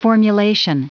Prononciation du mot formulation en anglais (fichier audio)
Prononciation du mot : formulation